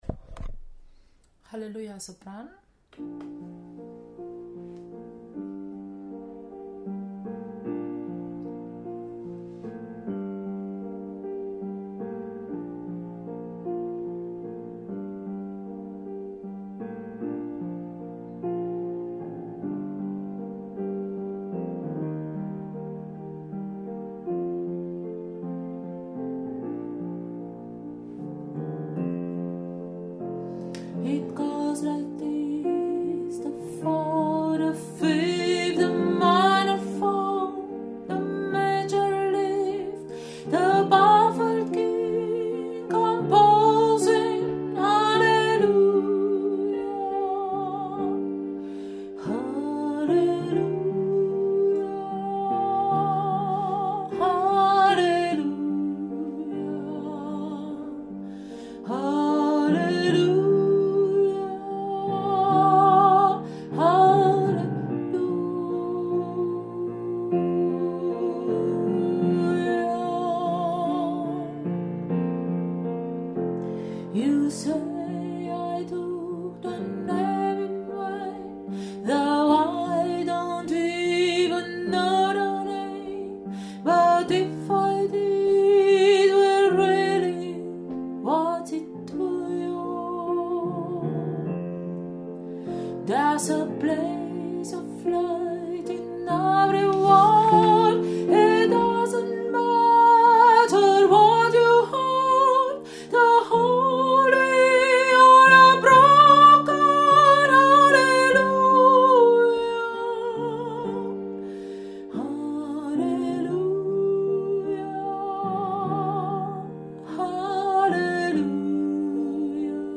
Hallelujah – Sopran
HallelujahCohen-Sopran.mp3